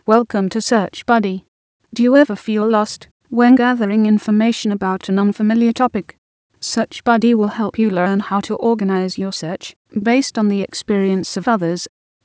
In addition to the images you can see of the GoogleBuddy and SearchBuddy interfaces there were also voice driven instructions present to help reinforce the learning of the steps of the search strategy.
welcome audio that was present in the introductory tutorial.